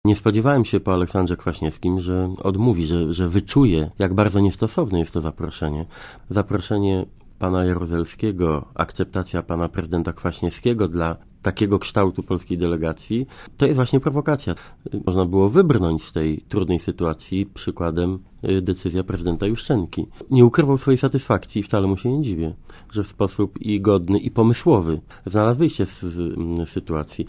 Mówi Donald Tusk
tusk_o_moskwie.mp3